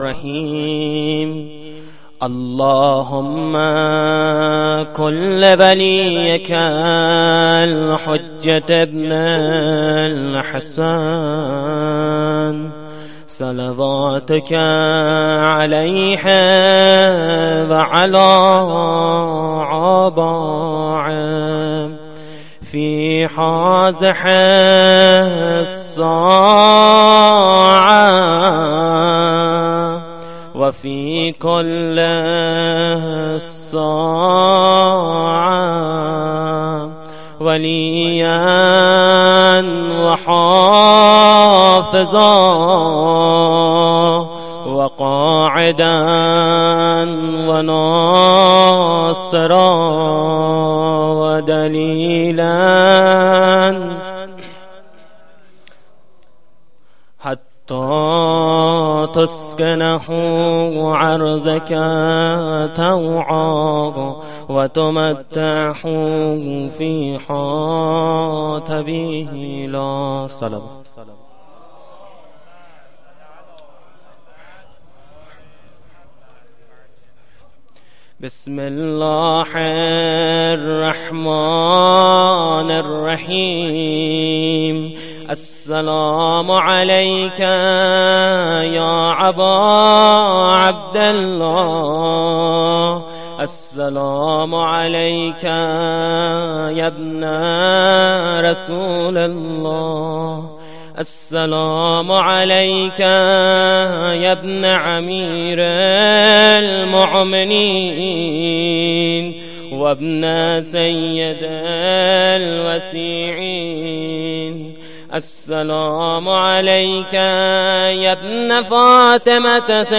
خیمه گاه - هیئت مهدیه احمد آباد - زیارت عاشورا-شب ششم محرم97-مهدیه احمدآباد